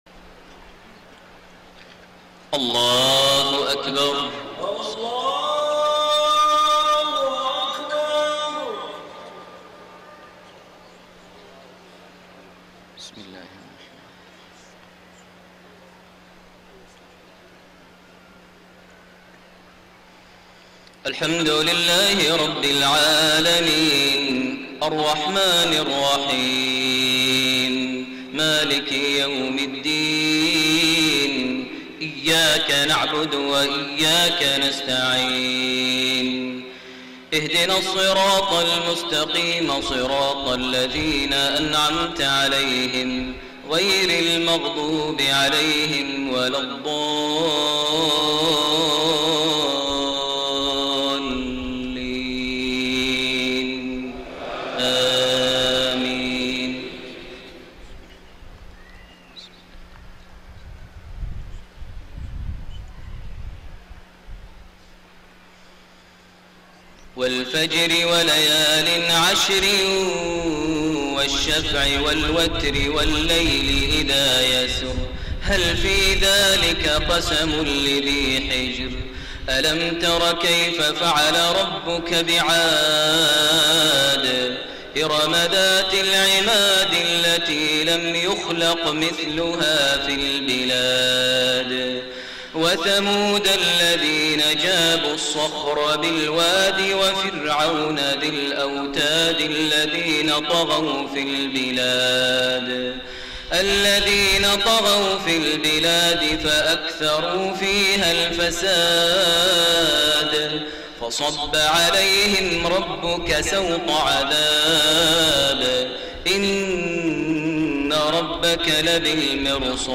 صلاة العشاء8-5-1431 سورتي الفجر والشمس > 1431 هـ > الفروض - تلاوات ماهر المعيقلي